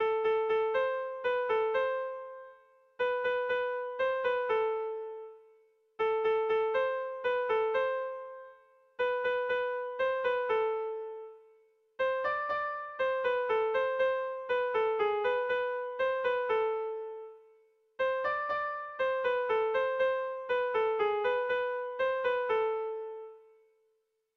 Lauko txikia (hg) / Bi puntuko txikia (ip)
A-B